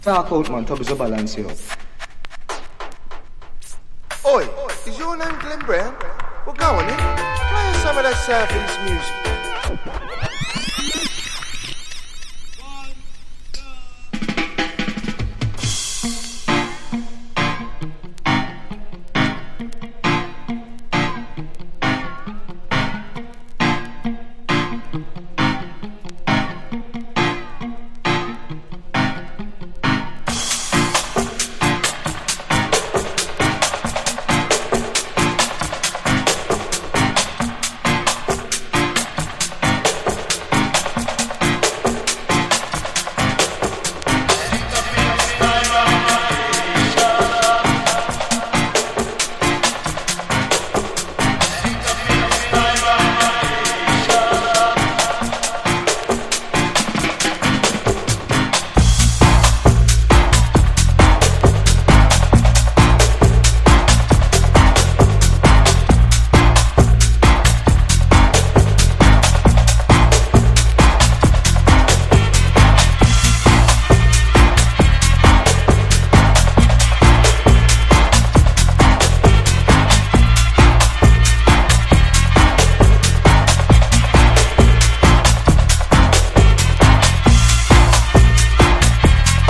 A面の絶妙なネタのカット・アップがグルーヴを生んだキラーなステッパーズ・リミックス最高！